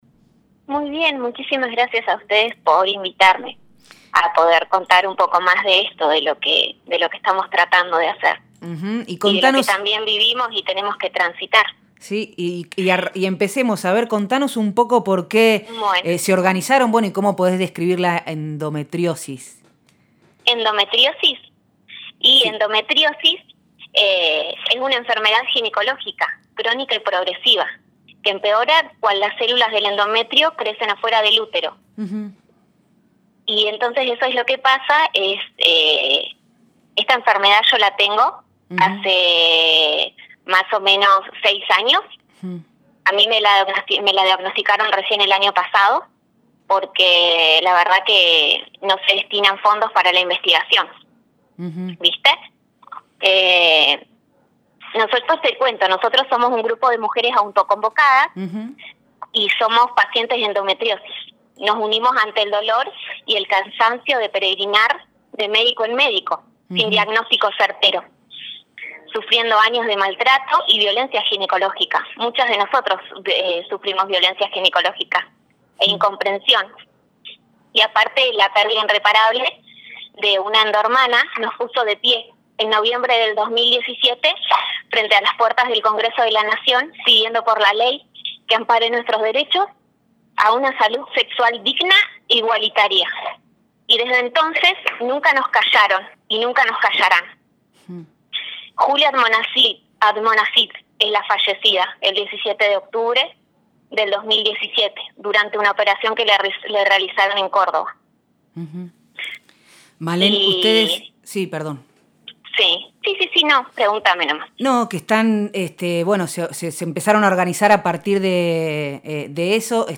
Nosotres les Otres, lunes de 18 a 20 por FM Horizonte 94.5